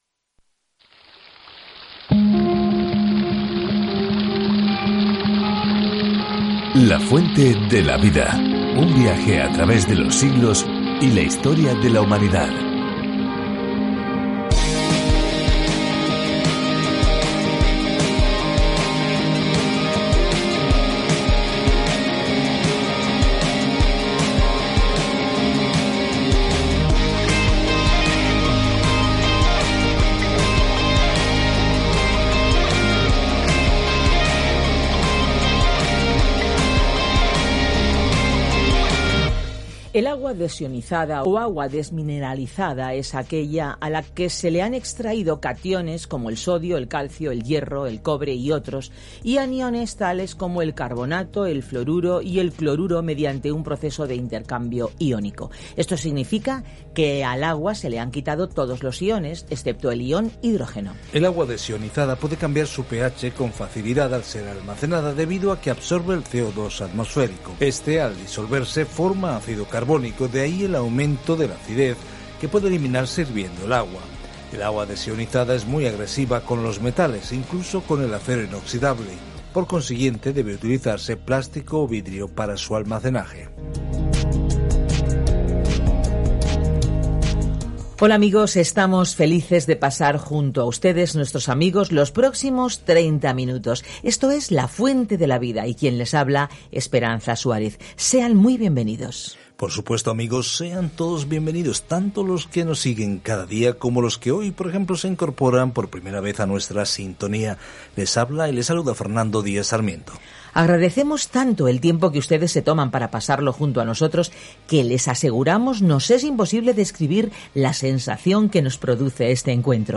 Scripture Joel 2:26-32 Joel 3:1 Day 6 Start this Plan Day 8 About this Plan Dios envía una plaga de langostas para juzgar a Israel, pero detrás de su juicio hay una descripción de un futuro “día del Señor” profético cuando Dios finalmente dé su opinión. Viaja diariamente a través de Joel mientras escuchas el estudio de audio y lees versículos seleccionados de la palabra de Dios.